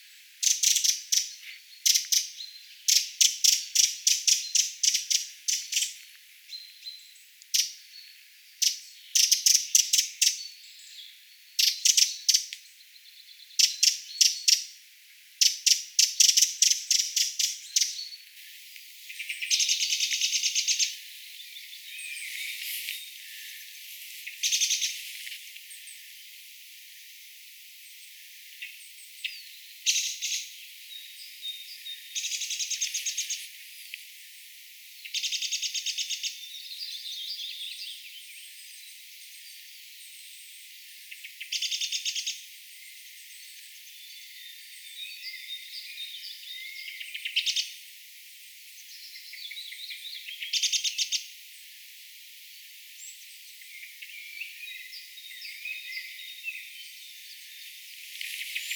Laulu- ja punakylkirastas huomioääntelivät
vertailu: punakylkirastaan ja laulurastaan
huomioääntelyä
vertailu_kahden_pikkurastaamme_huomioaanivertailu_ensin_punakylkirastas_ja_sitten_laulurastas.mp3